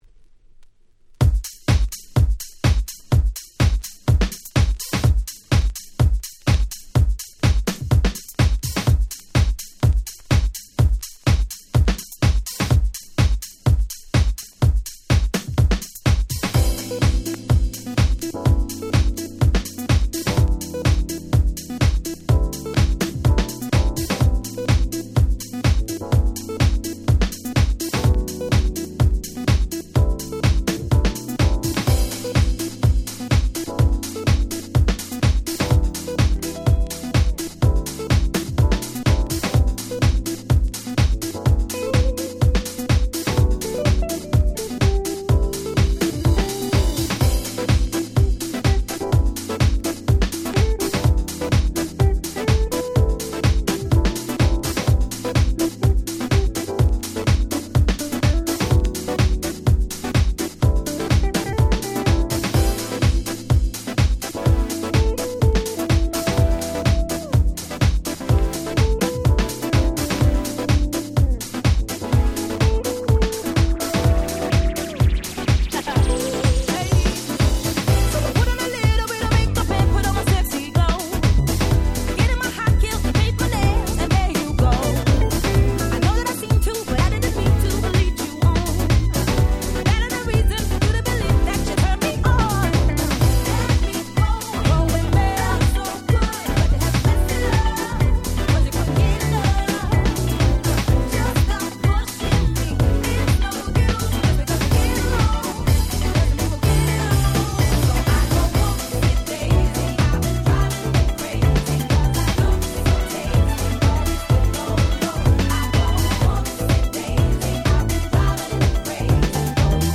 House Remixも最強！！